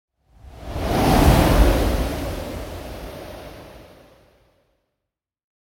دانلود آهنگ باد 16 از افکت صوتی طبیعت و محیط
دانلود صدای باد 16 از ساعد نیوز با لینک مستقیم و کیفیت بالا
جلوه های صوتی